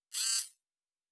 pgs/Assets/Audio/Sci-Fi Sounds/Mechanical/Servo Small 9_1.wav at 7452e70b8c5ad2f7daae623e1a952eb18c9caab4
Servo Small 9_1.wav